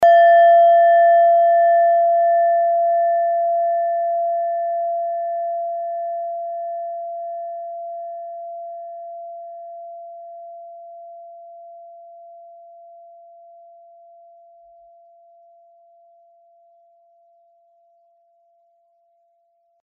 Klangschale Nepal Nr.18
Klangschale-Gewicht: 490g
Klangschale-Durchmesser: 11,2cm
(Ermittelt mit dem Filzklöppel)
Der Chironton liegt bei 171,80 Hz und ist die 38. Oktave der Umlauffrequenz des Chirons um die Sonne.
In unserer Tonleiter liegt dieser Ton nahe beim "F".
klangschale-nepal-18.mp3